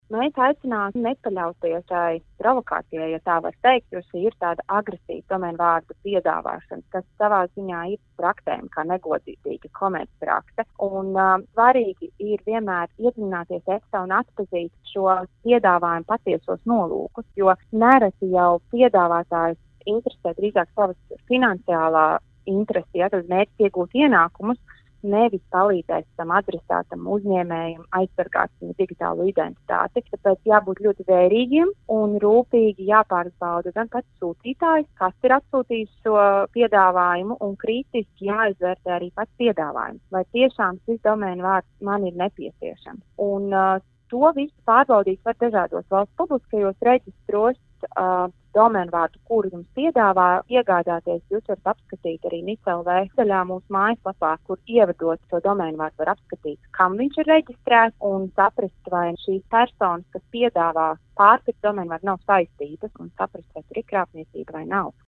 RADIO SKONTO Ziņās par maldinošiem piedāvājumiem iegādāties domēna vārdus